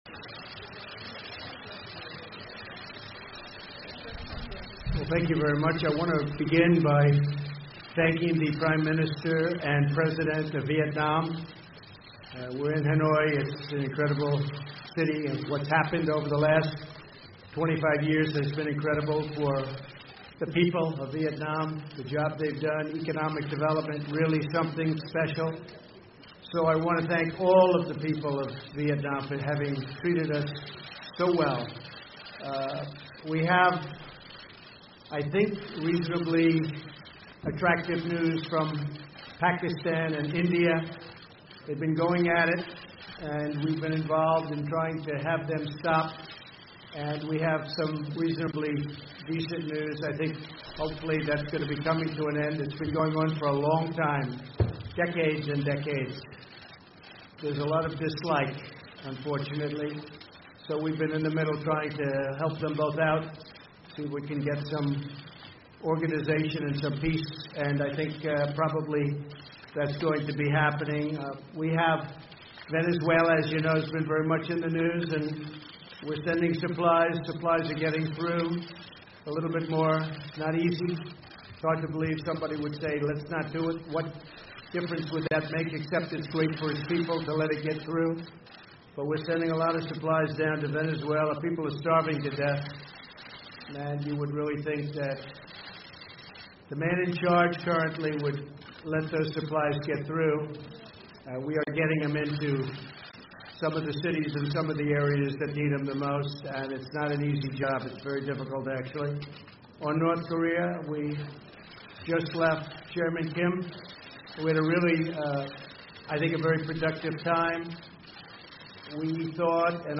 河内美朝峰会后特朗普记者会全程实录
美国总统特朗普星期三(2月28日)在河内无协议而结束第二次美朝峰会后举行了有世界各地几百名新闻业者参加的记者会。他谈到了为什么在峰会上没有与朝鲜领导人金正恩达成协议的原因，还讨论了世界和美国发生的事情，包括美中贸易谈判。